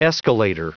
Prononciation du mot escalator en anglais (fichier audio)
Prononciation du mot : escalator